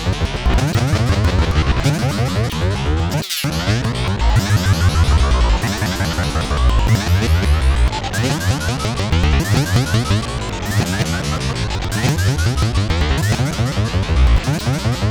• game bouncy fracture textured fx.ogg